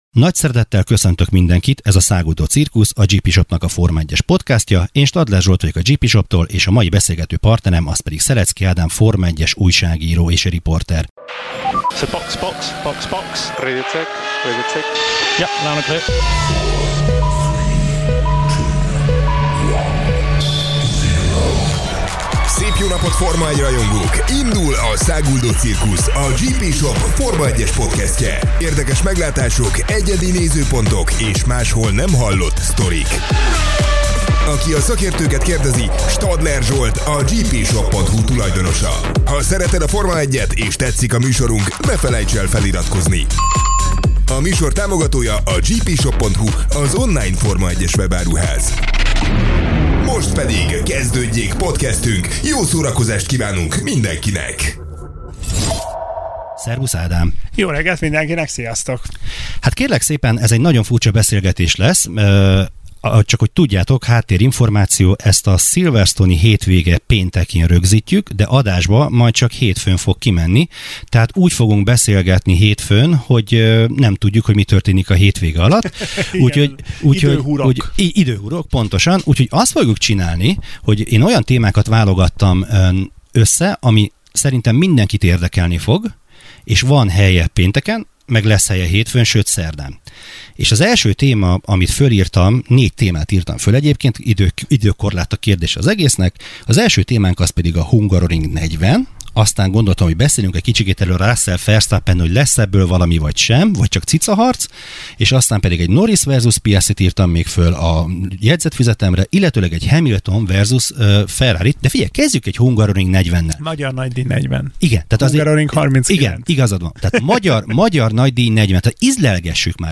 Beszélgetés